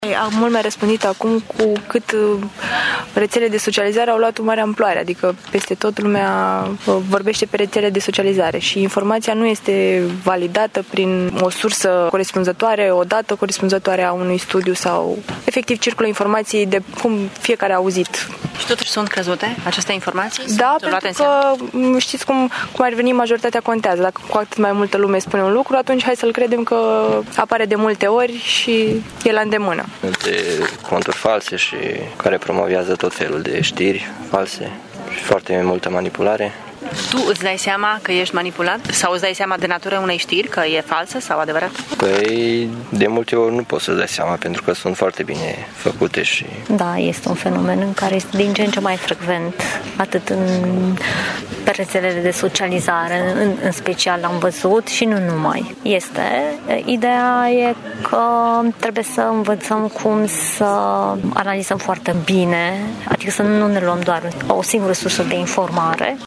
Studenții Universității de Medicină din Târgu-Mureș sunt conștienți de pericolul reprezentat de știrile false, prezente mai ales pe rețelele de socializare: